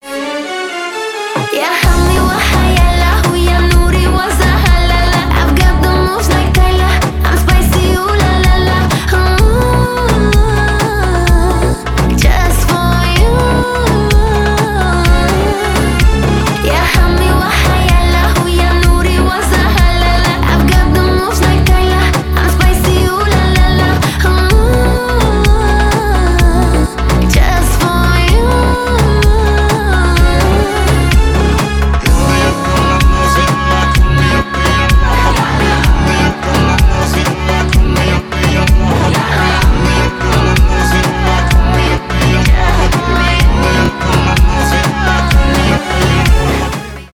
восточные , арабские , поп